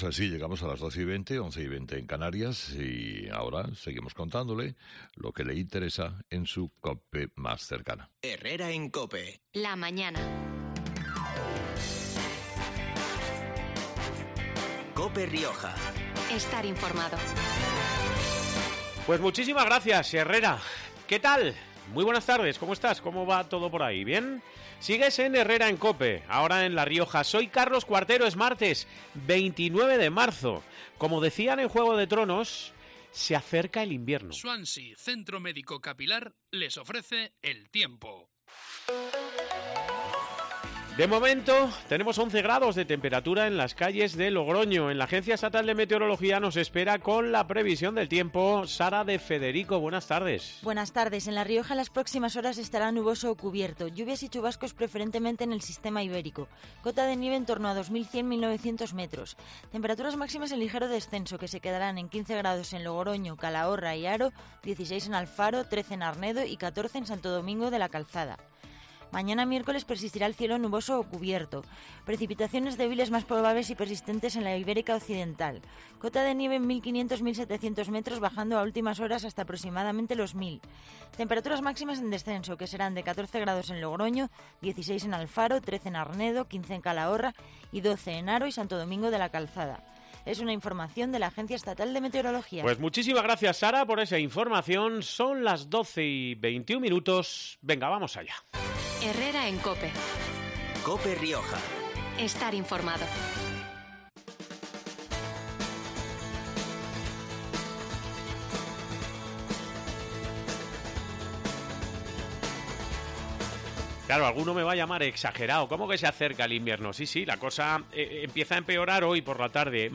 Villamediana cierra con éxito el Campeonato Nacional de Kárate La alcaldesa villametrense, Ana Belén Martínez , ha pasado este último martes del mes de marzo por los micrófonos de COPE Rioja para hacer balance de una competición que entre deportistas, jueces y público ha reunido a unas 2.000 personas en el municipio, de viernes a domingo .